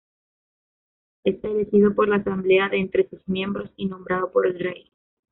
Pronounced as (IPA) /de/